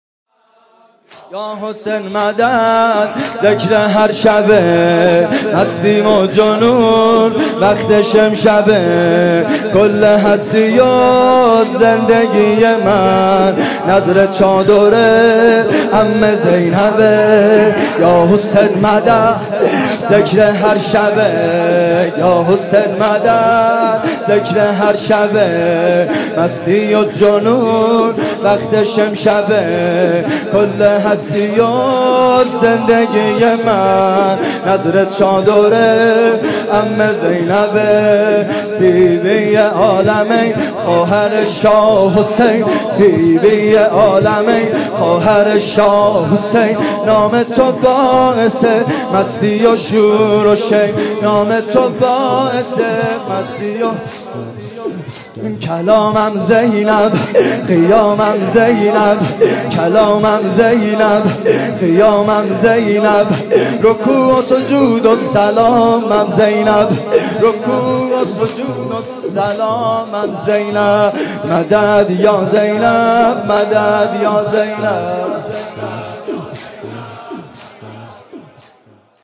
زمینه زیبا